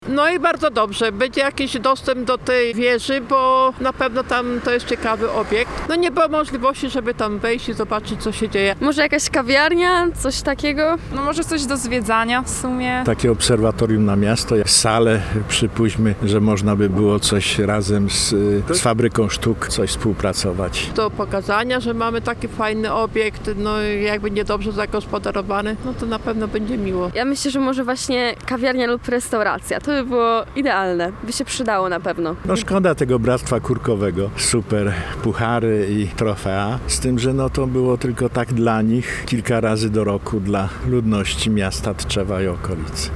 Co o planach rewitalizacji wieży ciśnień w Tczewie sądzą mieszkańcy?